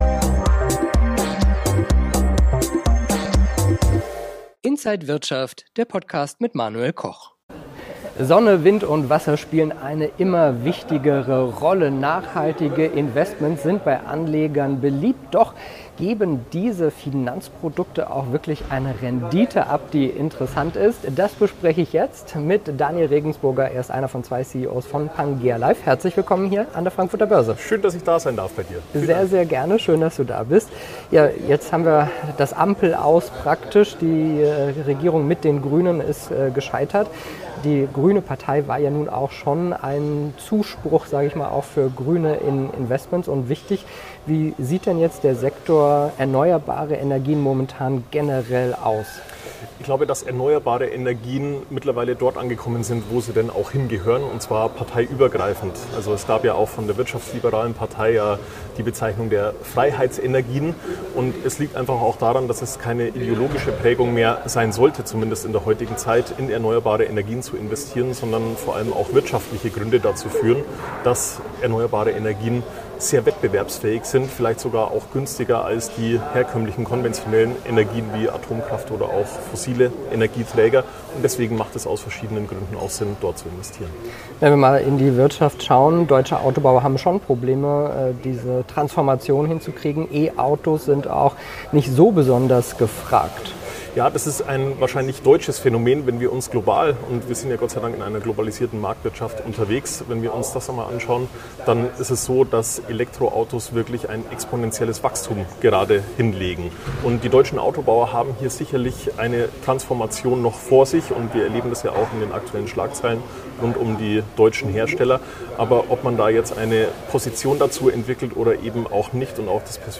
an der Frankfurter Börse